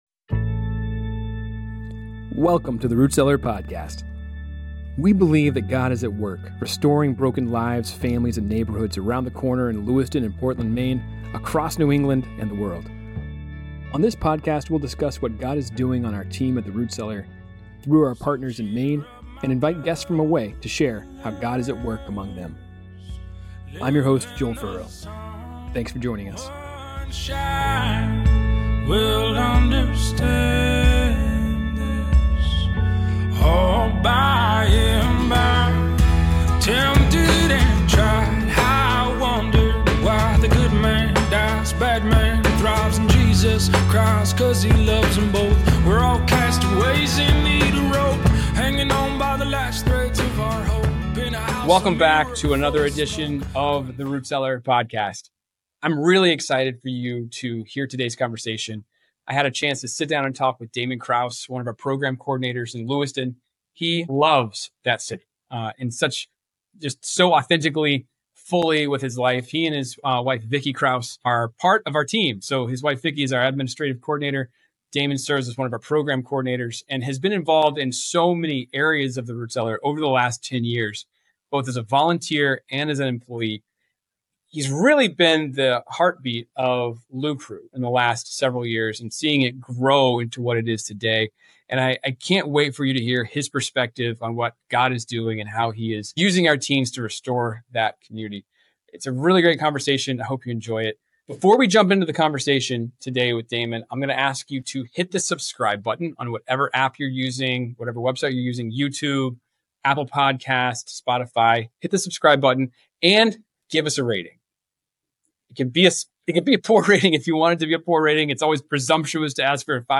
The conversation highlights the importance of community, mentorship, and the empowerment of youth through work and life-skills initiatives.